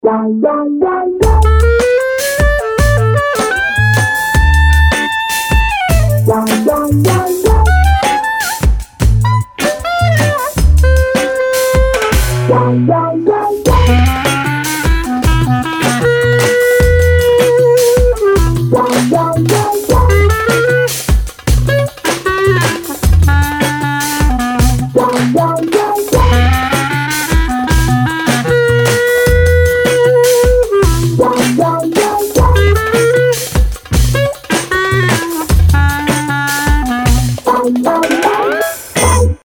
• Качество: 192, Stereo
без слов
Саксофон